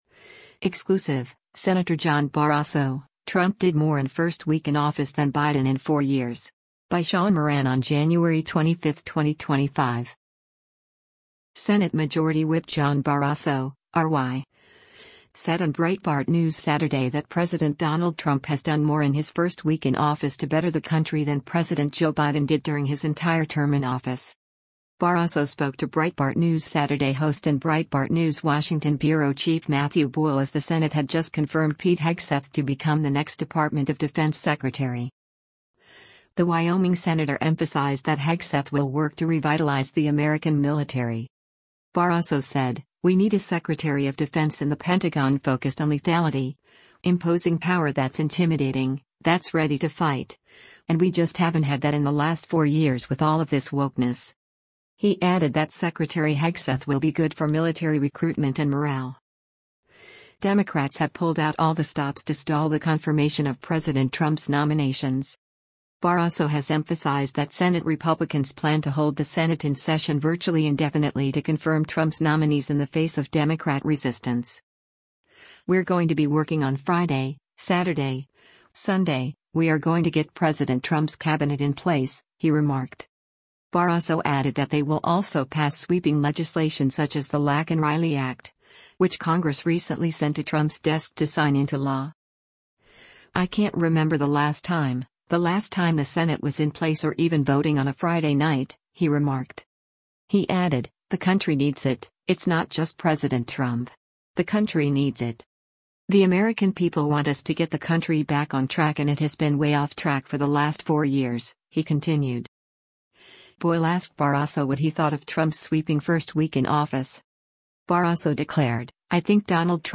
Senate Majority Whip John Barrasso (R-WY)  said on Breitbart News Saturday that President Donald Trump has done more in his first week in office to better the country than President Joe Biden did during his entire term in office.
Breitbart News Saturday airs on SiriusXM Patriot 125 from 10:00 to 1:00 P.M. Eastern.